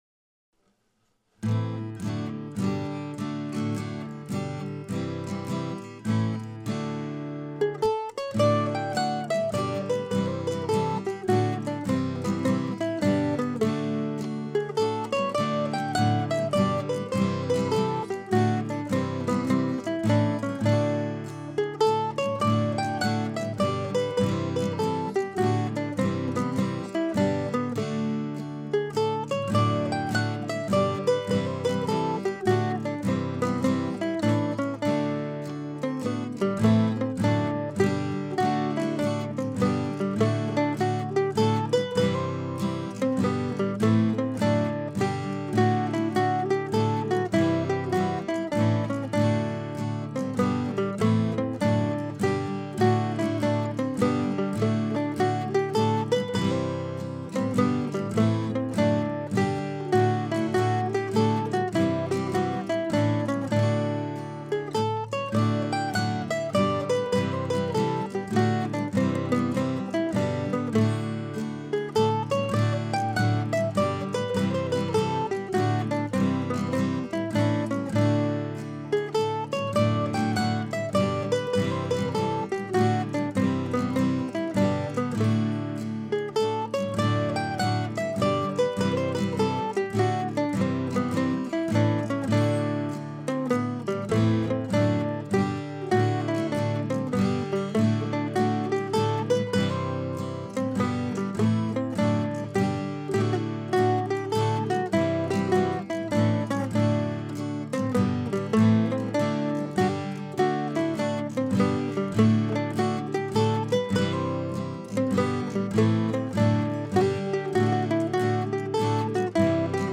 Before heading out this morning on a journey to see the cherry blossoms in Washington, D.C. (and play a few dances this weekend) I thought I would post this slightly quirky waltz.
I'm not sure I would recommend playing it at a dance but it's a satisfying exercise on the mandolin and could make for a relaxing listening piece.
SE2005waltz.mp3